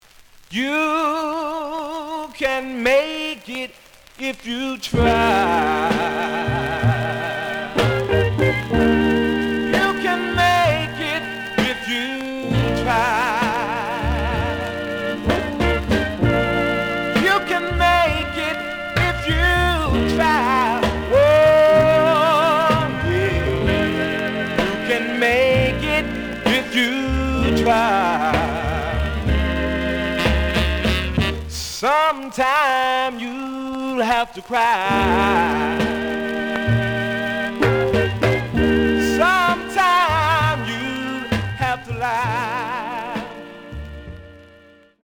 The audio sample is recorded from the actual item.
●Genre: Rhythm And Blues / Rock 'n' Roll
Slight affect sound.